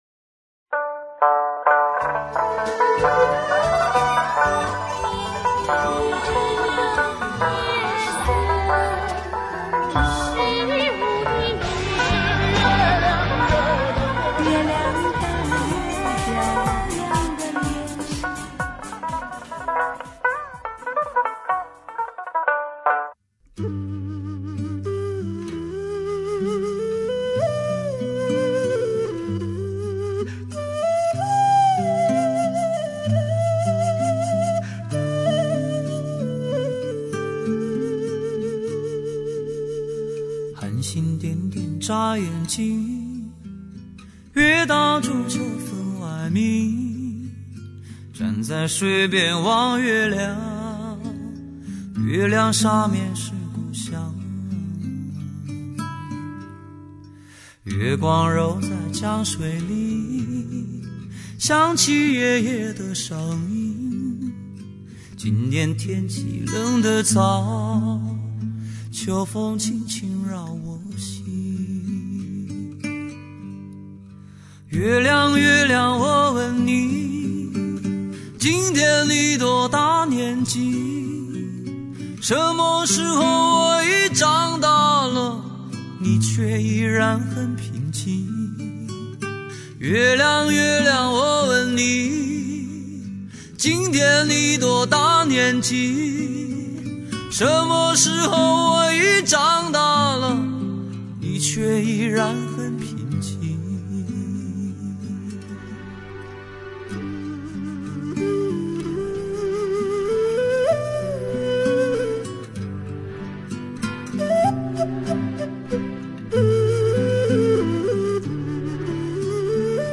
一、2004 特别混音版：